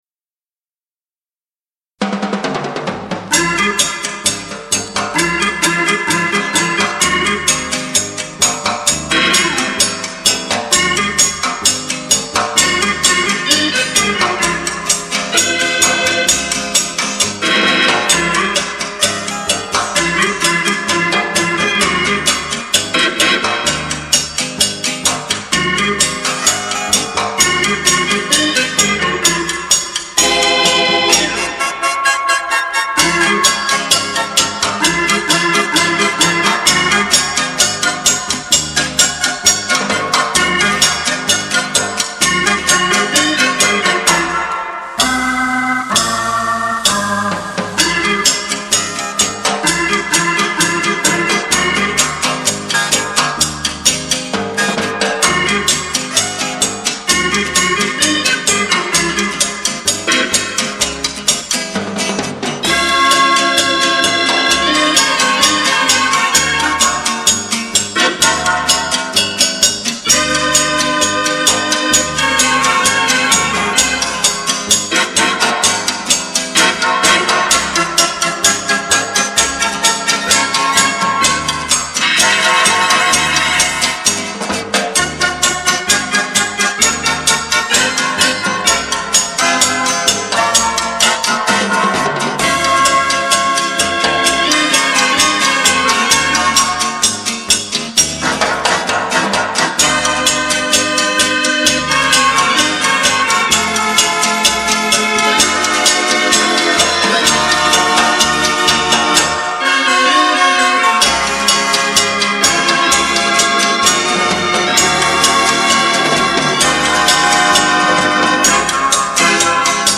CHA-CHA-CHA